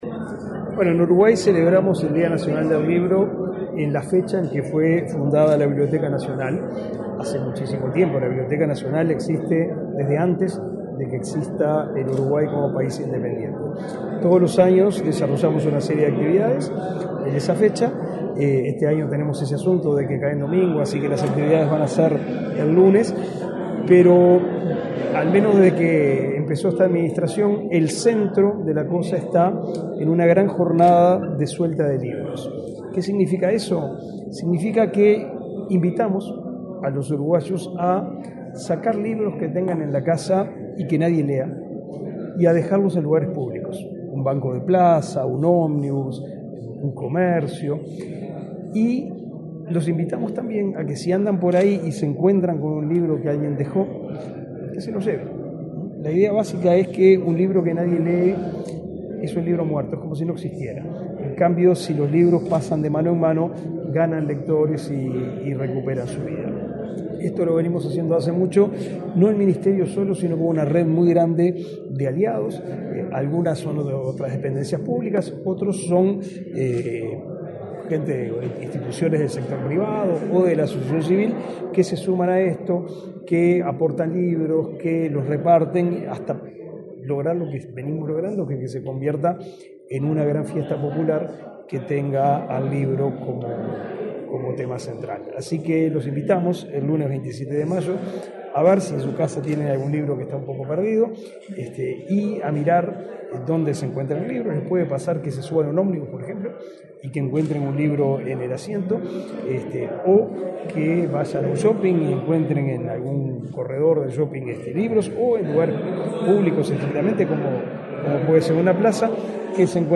Declaraciones del ministro de Educación y Cultura, Pablo da Silveira
Declaraciones del ministro de Educación y Cultura, Pablo da Silveira 08/05/2024 Compartir Facebook X Copiar enlace WhatsApp LinkedIn Este miércoles 8, el director de la Biblioteca Nacional, Valentín Trujillo, y el ministro de Educación y Cultura, Pablo da Silveira, participaron en el lanzamiento del Día Nacional del Libro y un nuevo aniversario de la Biblioteca Nacional. Luego, el secretario de Estado dialogó con la prensa.